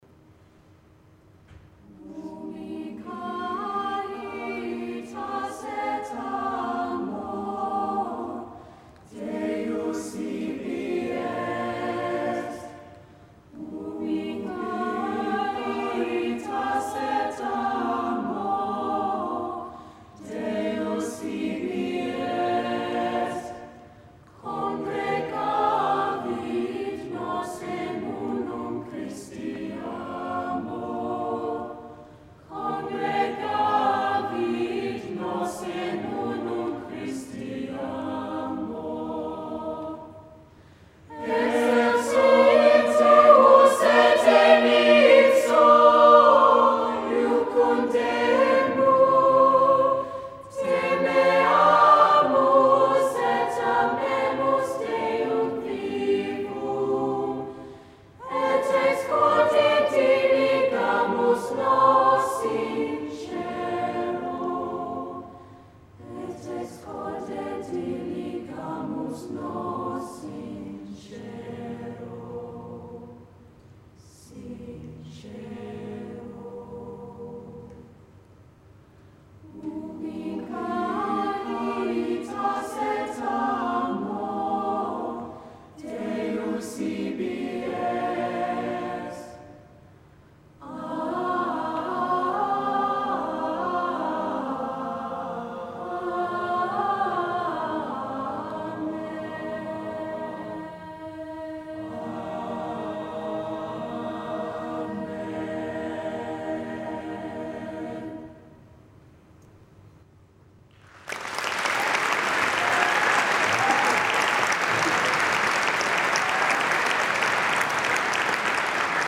Recordings from The Big Sing National Final.
St Peter's College Ubi Caritas Loading the player ...